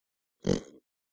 Pig Sound Button - Free Download & Play
Animal Sounds Soundboard357 views